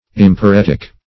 Search Result for " emporetic" : The Collaborative International Dictionary of English v.0.48: Emporetic \Em`po*ret"ic\, Emporetical \Em`po*ret"ic*al\, a. [L. emporeticus, Gr.